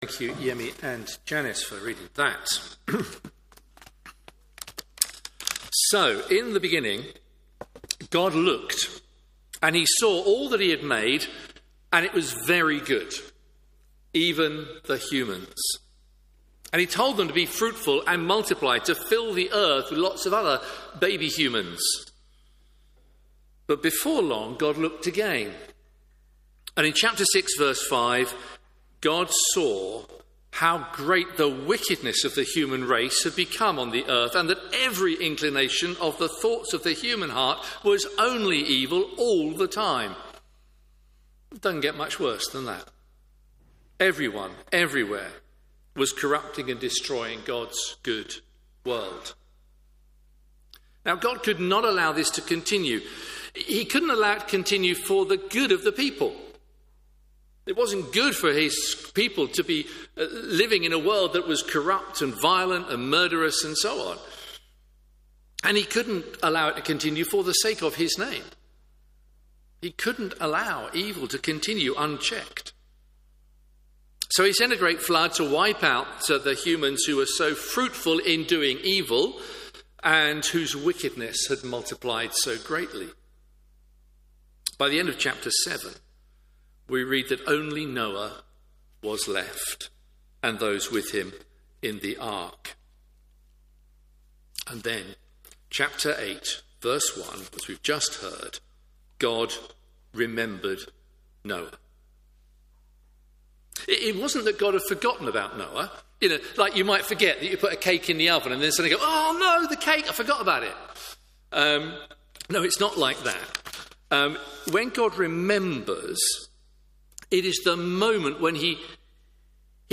Media for Morning Service on Sun 20th Jul 2025 10:30 Speaker: Passage: Genesis 8, Genesis 9 Series: Genesis Theme: Sermon In the search box please enter the sermon you are looking for.